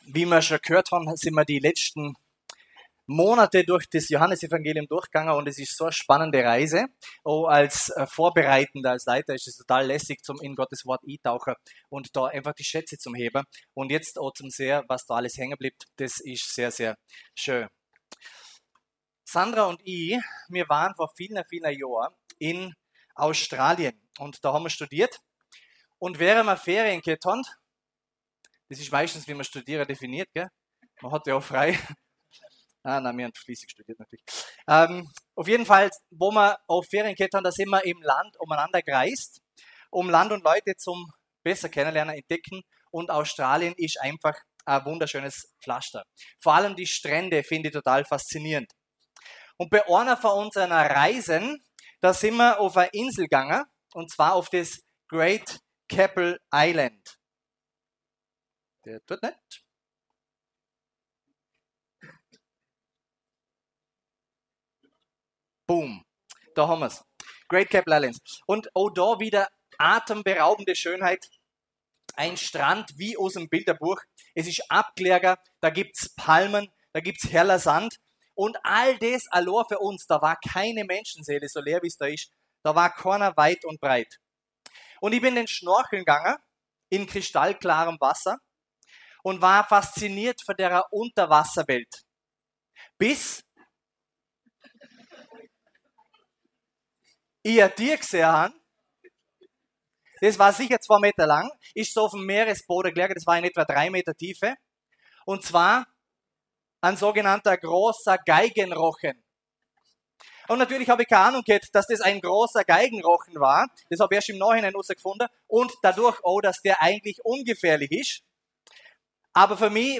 Alt-Jung Gottesdienst – Johannes 14,1-6